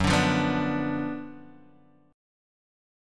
F#M7sus4#5 chord